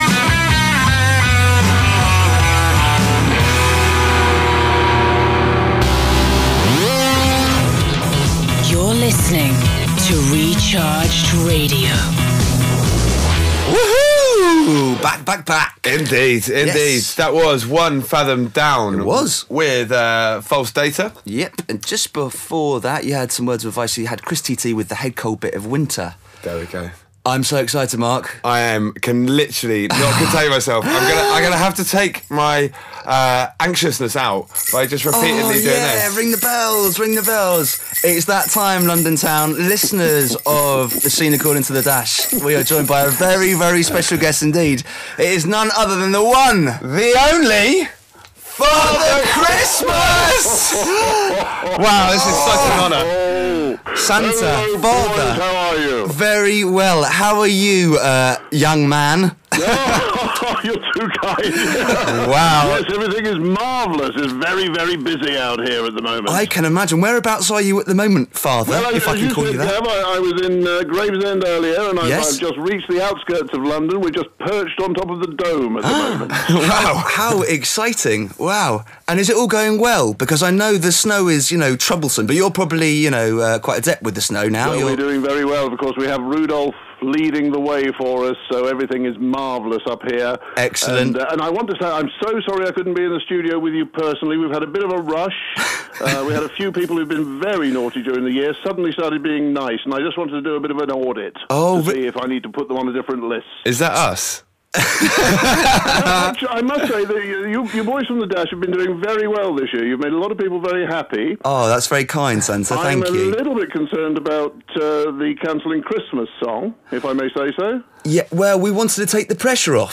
Until it somehow formed itself as an occasional arts-themed audio podcast.